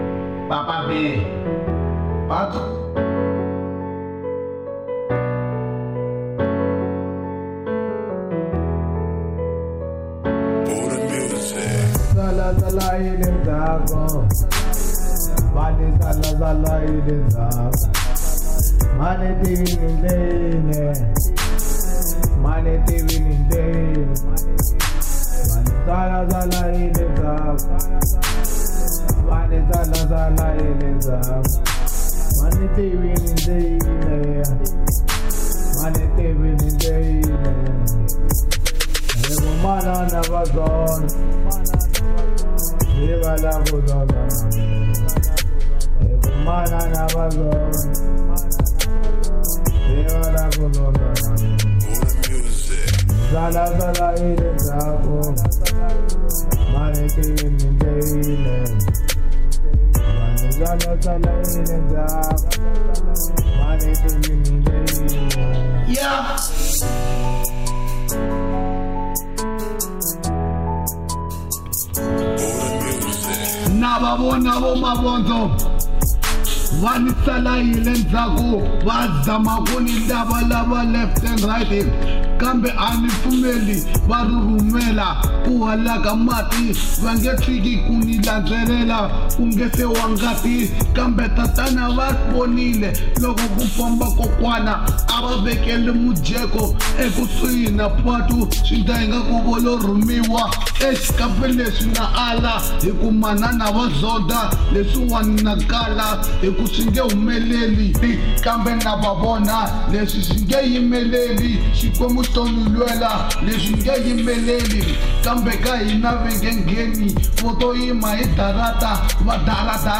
Afro Pop Size